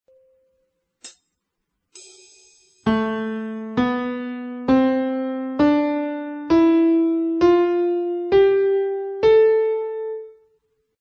qui la scala di La minore naturale
scala_min_naturale_(64,kb.mp3